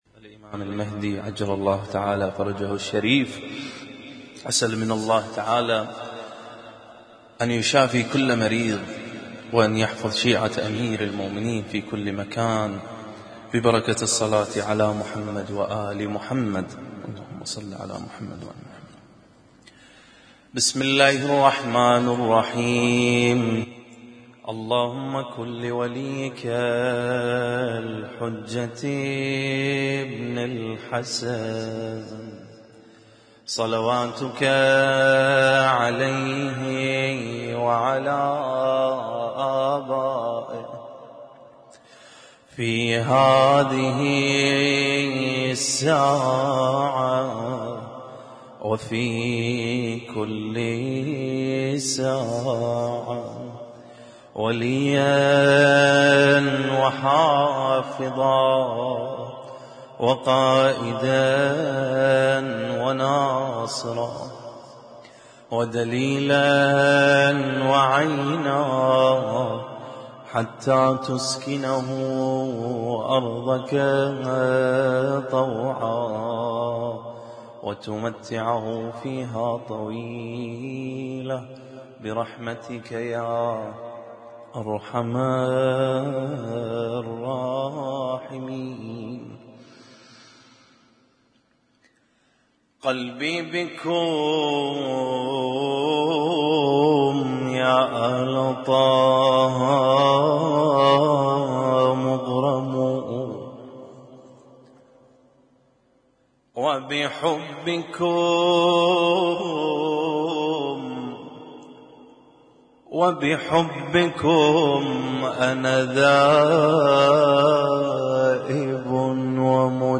Husainyt Alnoor Rumaithiya Kuwait
اسم التصنيف: المـكتبة الصــوتيه >> المواليد >> المواليد 1441